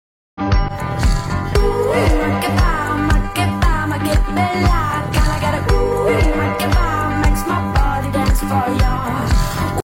it has sm good beats😍